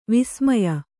♪ vismaya